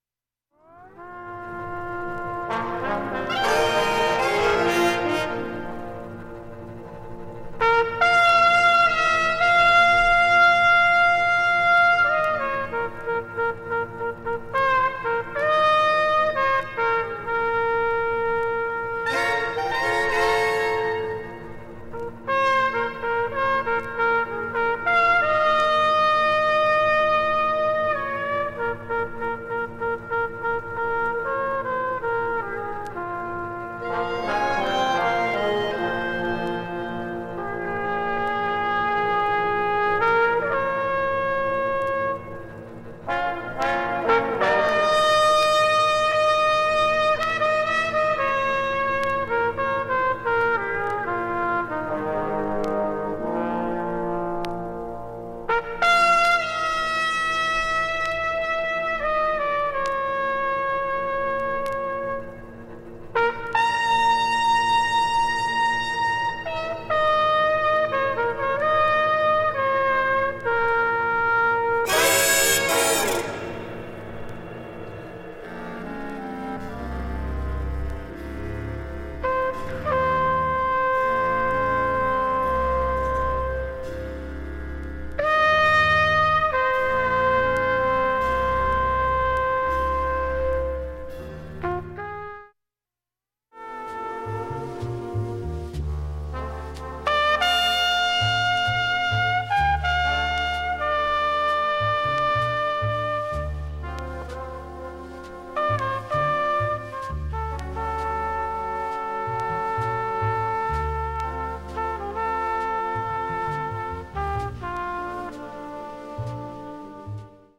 2,(1m33s〜)B-3中盤にかすかなプツが9回出ます。